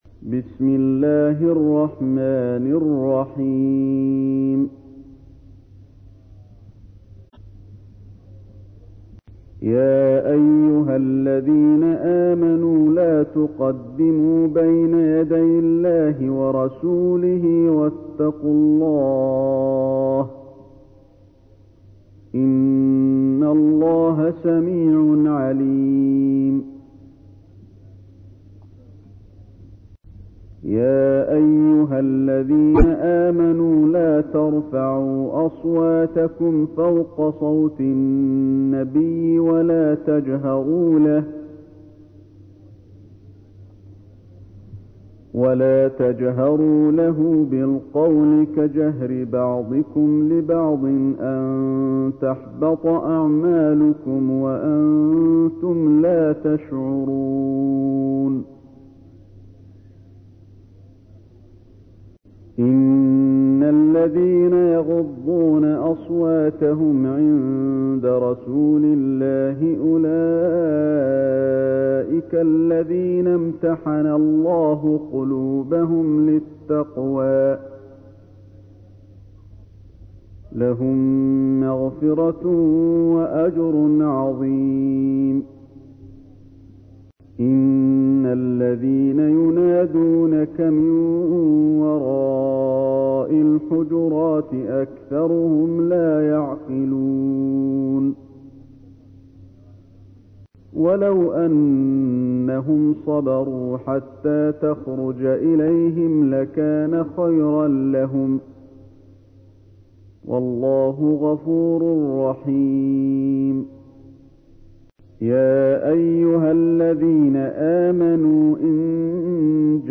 تحميل : 49. سورة الحجرات / القارئ علي الحذيفي / القرآن الكريم / موقع يا حسين